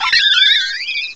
cry_not_staravia.aif